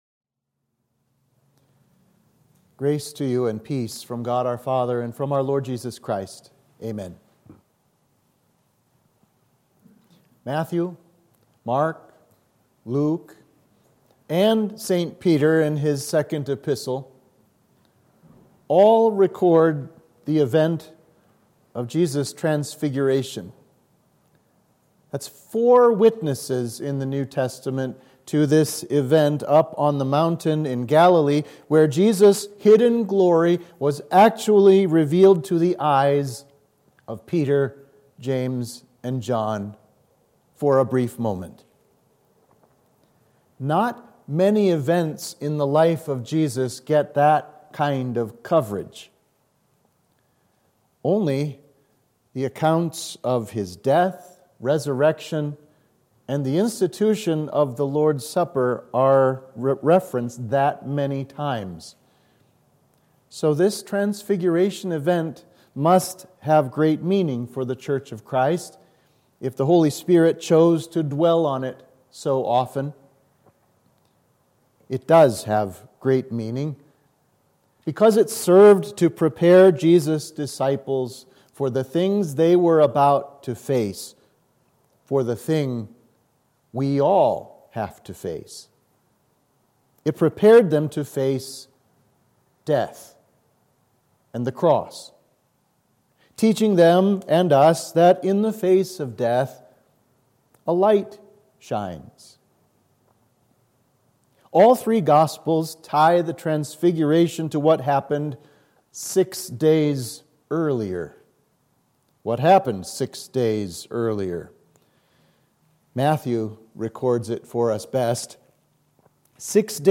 Sermon for the Transfiguration of Our Lord